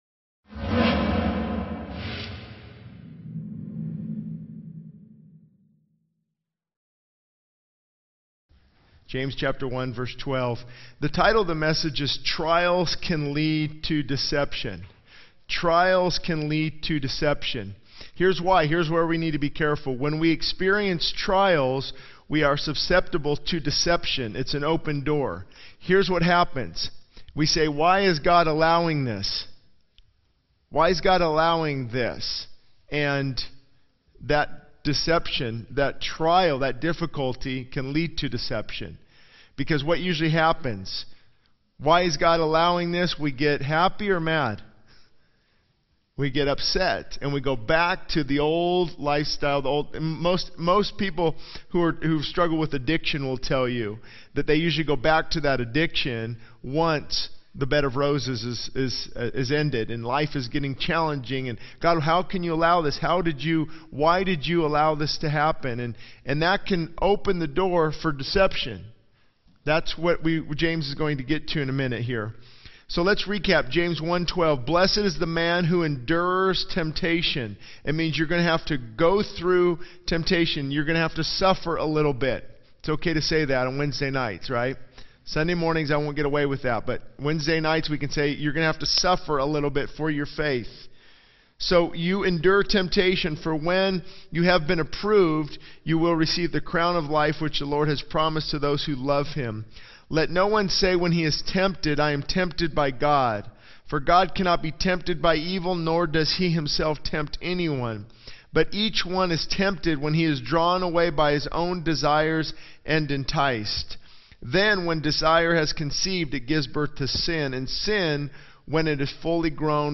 This sermon emphasizes the danger of trials leading to deception, highlighting the vulnerability we face during challenging times and the potential for deception when we question God's intentions. It explores the impact of trials on our faith and the importance of enduring temptation to receive the promised blessings.